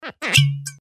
короткие
Звук доставания пробки из бутылки вина, например